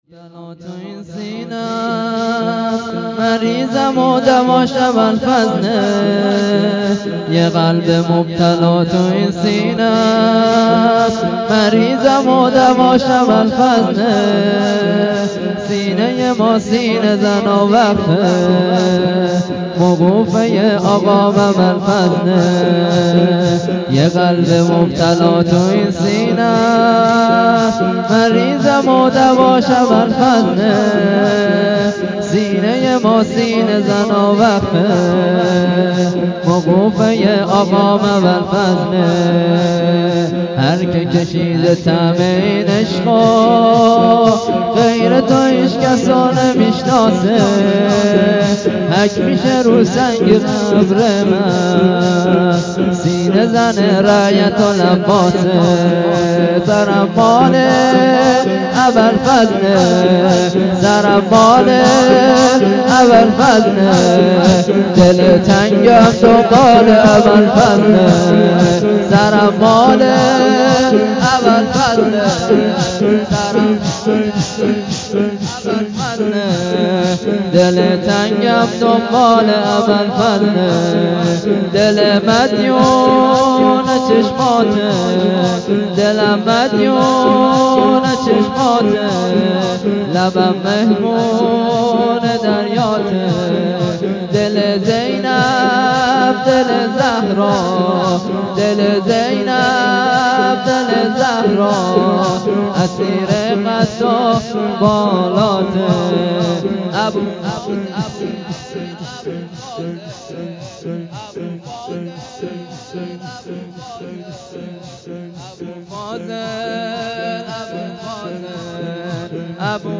شور حضرت عباس